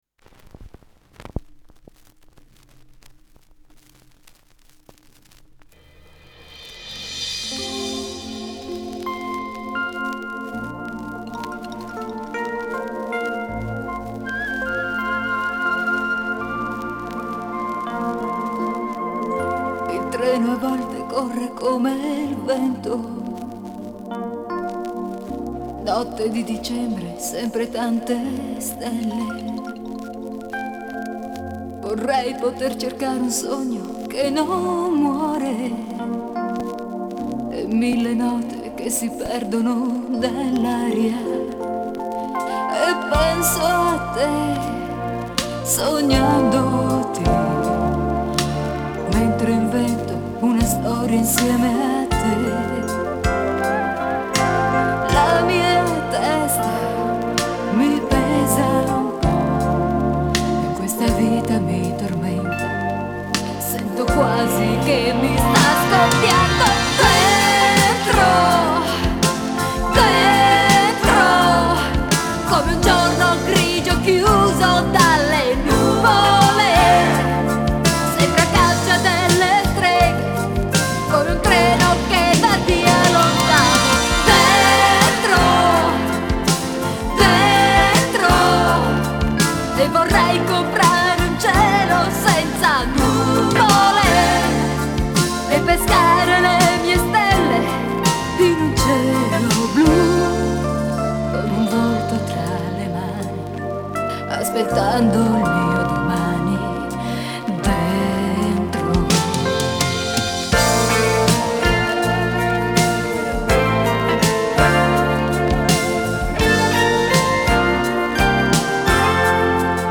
Genre:Electronic, Pop
Style:Italo-Disco, Chanson